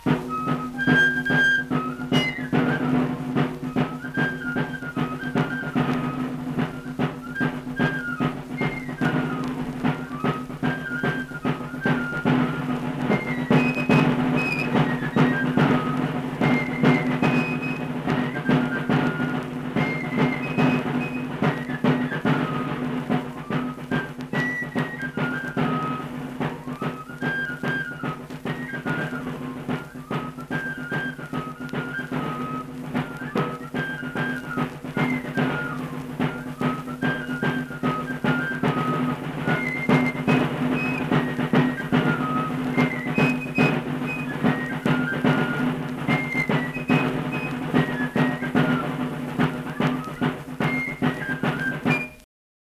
Accompanied fife and drum music
Performed in Hundred, Wetzel County, WV.
Instrumental Music
Drum, Fife